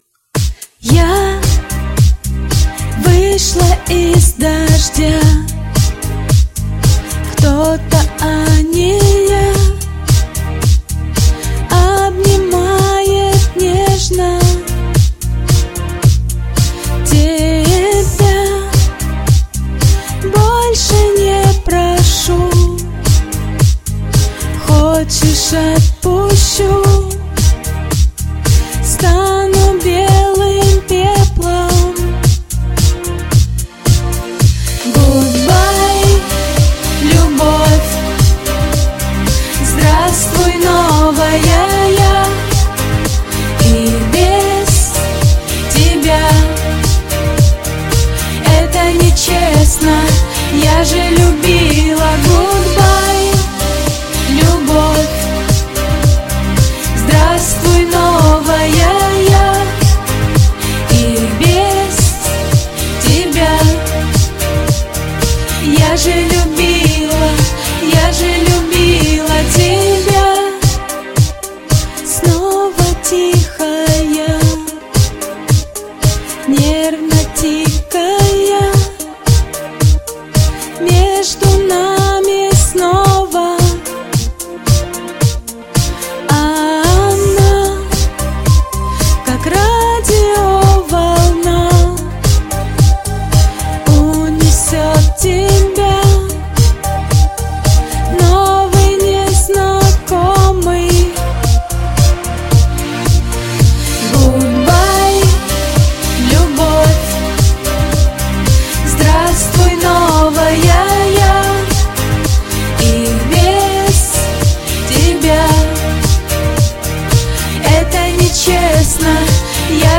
Песня немного легче в эмоциональном плане.